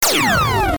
Added new weapon sounds: blaster, laser, pulse, wave, emitter, and one for the marauder canon.
laser3.ogg